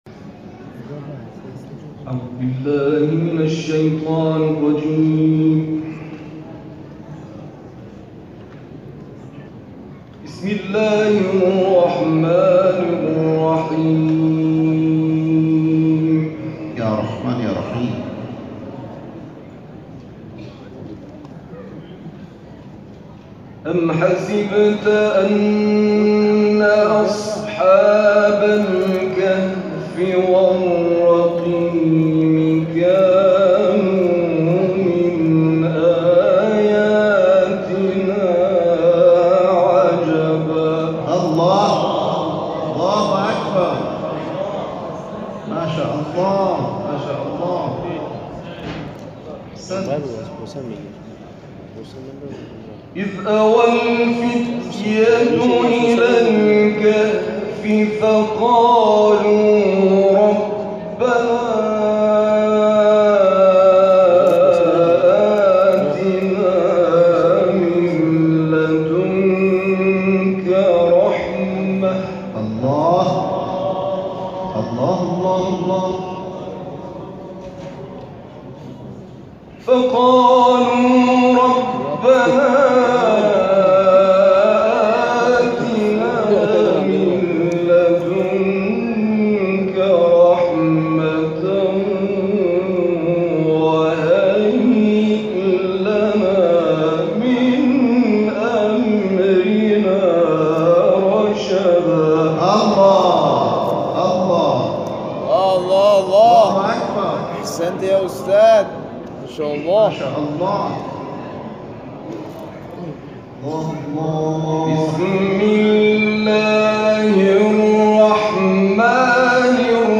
جدیدترین اجراهای 4 استاد تلاوت قرآن کشور در حرم امام رضا(ع) صوت - تسنیم